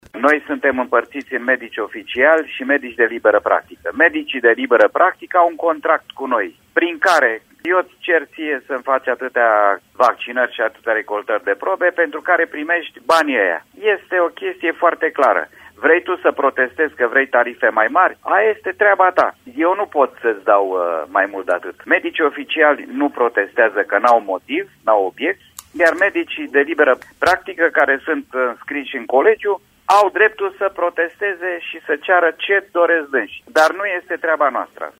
De la Brașov vor participa câteva zeci de medici de liberă p ractică, a declarat directorul Direcției Sanitar Veterinare Brașov, Dorin Enache: